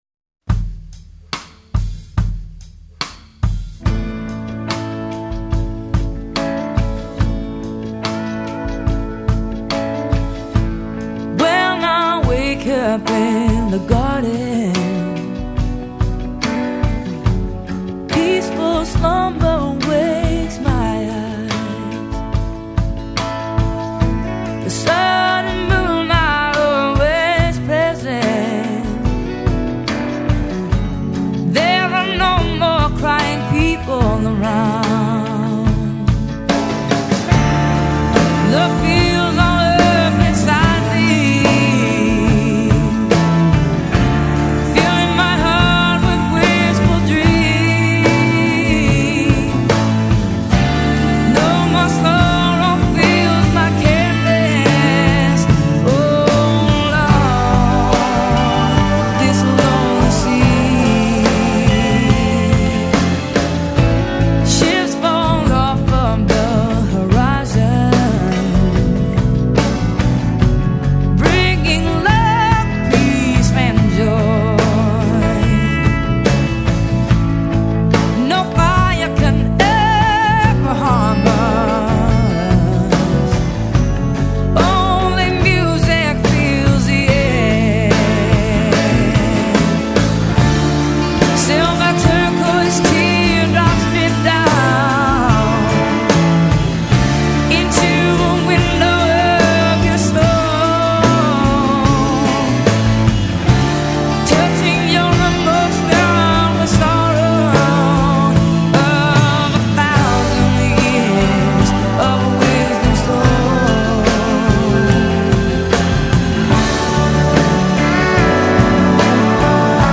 жизнеутверждающая